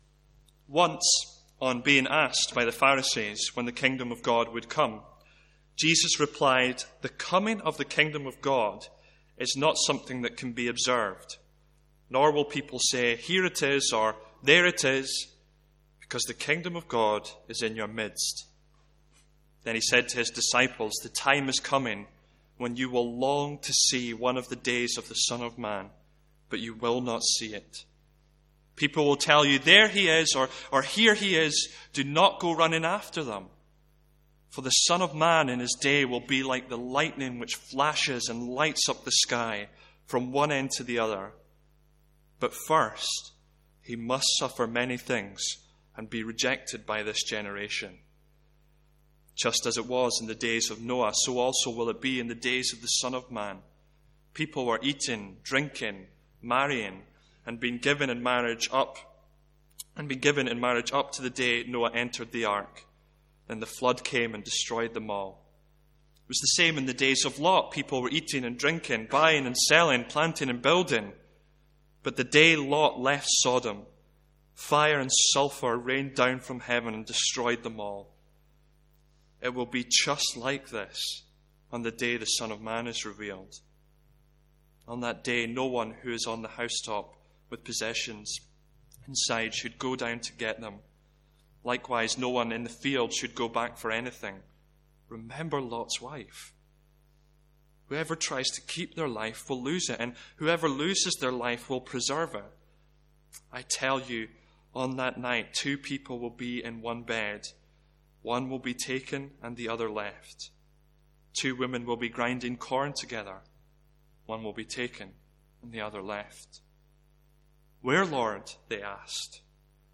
Sermons | St Andrews Free Church
From our morning series in Luke's Gospel.